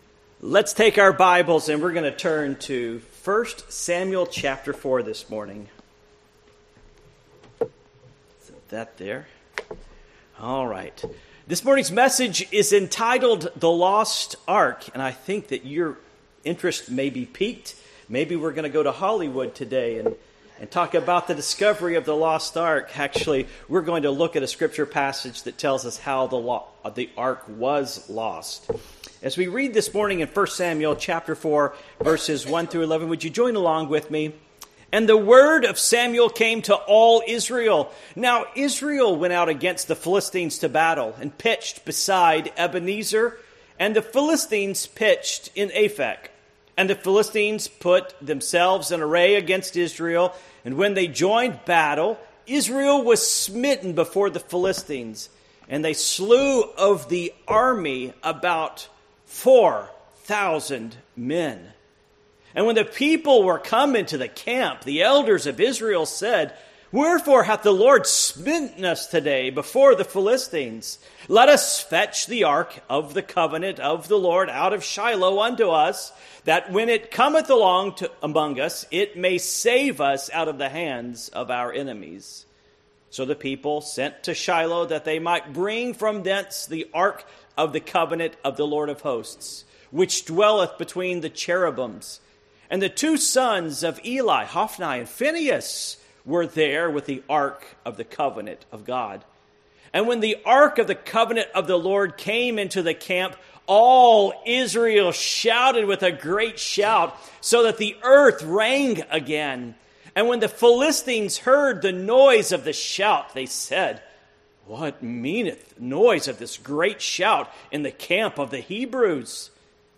1 Samuel 4:1-11 Service Type: Morning Worship 1 Samuel 4:1-11 1 And the word of Samuel came to all Israel.